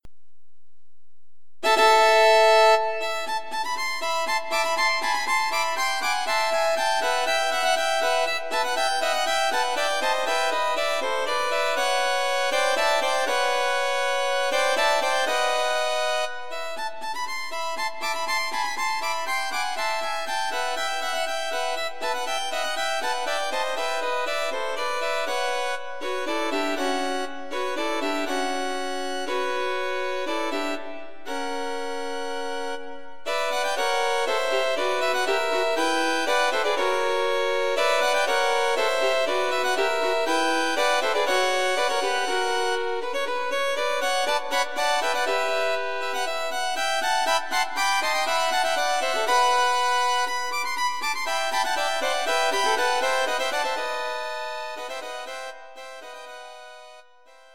Violin Trio
The first section is an opening flourish or fanfare
exploring some harmonic modulations.